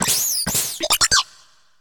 Cri de Gribouraigne dans Pokémon HOME.